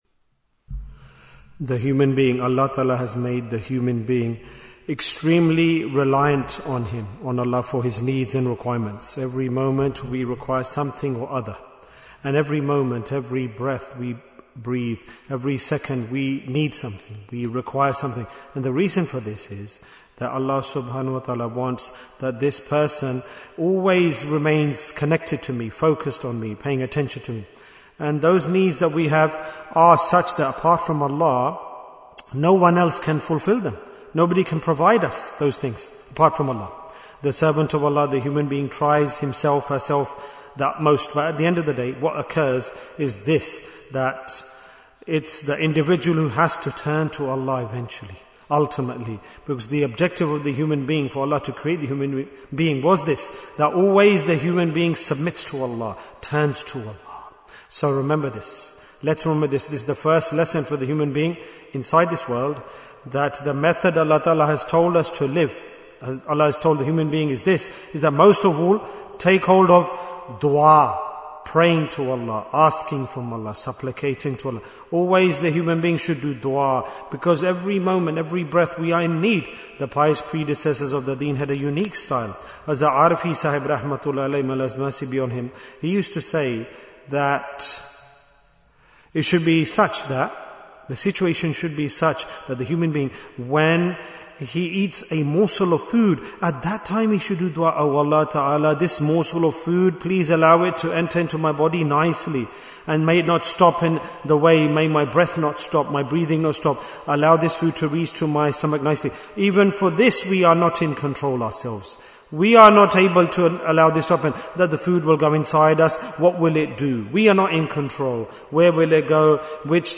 - Part 17 Bayan, 31 minutes 5th February, 2026 Click for Urdu Download Audio Comments Why is Tazkiyyah Important?